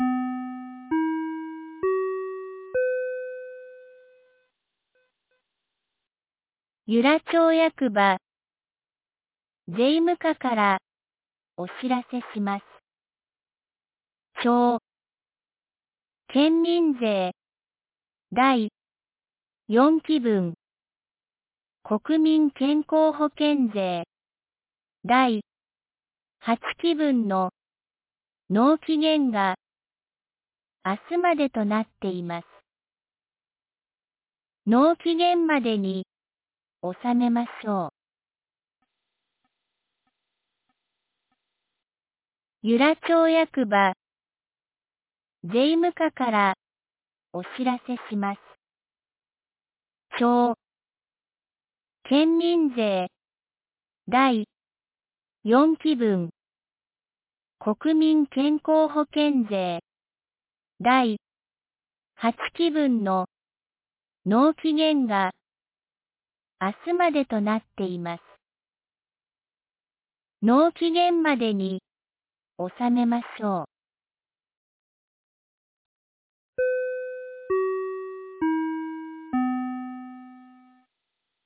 2026年02月01日 12時31分に、由良町から全地区へ放送がありました。